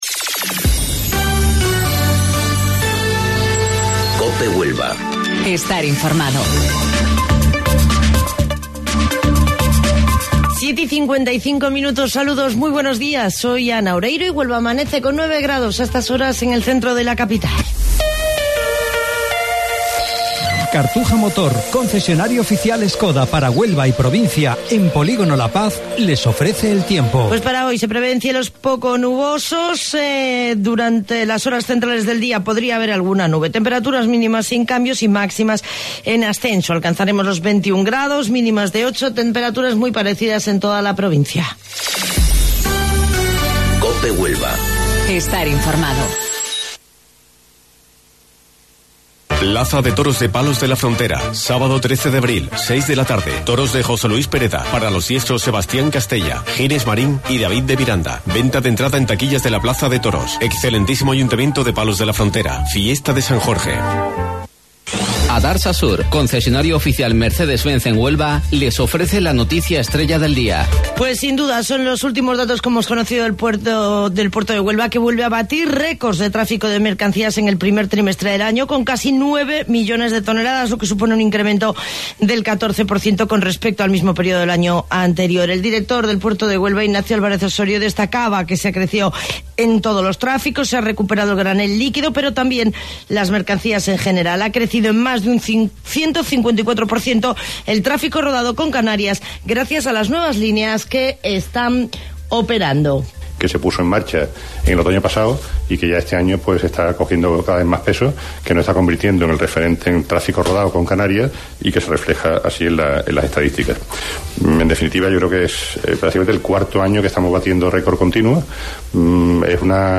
AUDIO: Informativo Local 07:55 del 10 de Abril